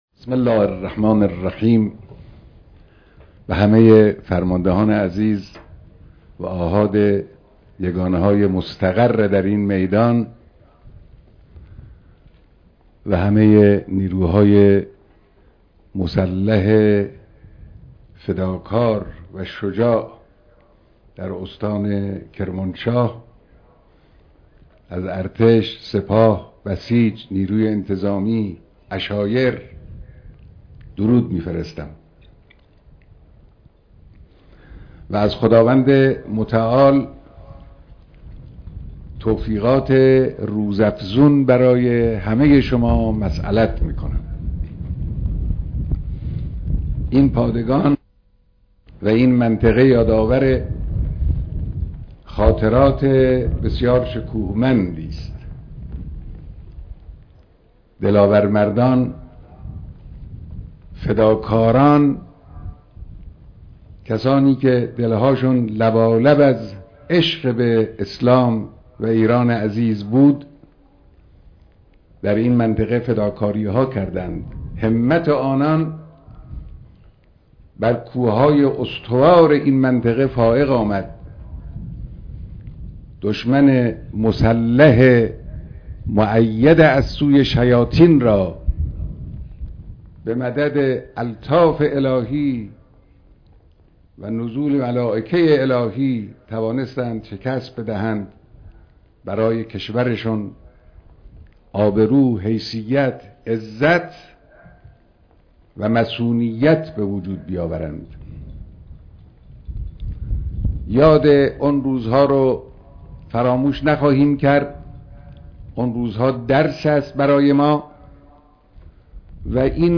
بیانات فرمانده کل قوا در مراسم مشترک نیروهای مسلح کرمانشاه